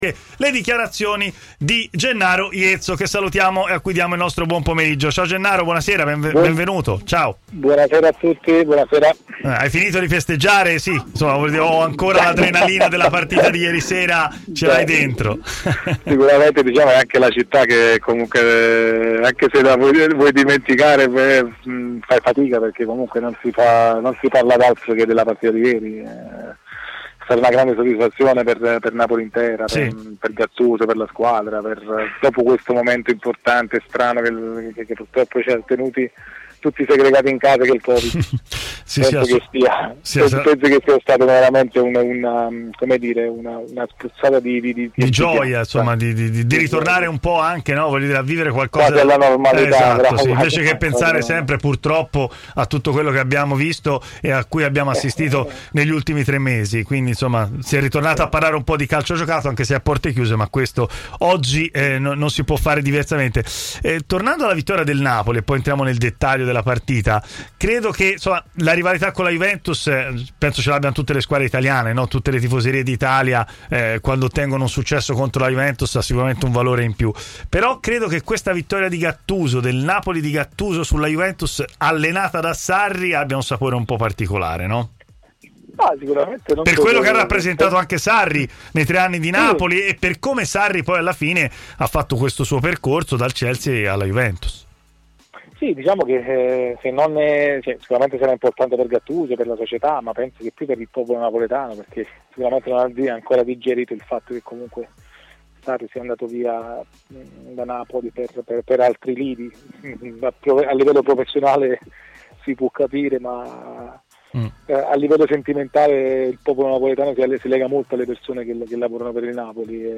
si è collegato in diretta con Stadio Aperto, trasmissione di TMW Radio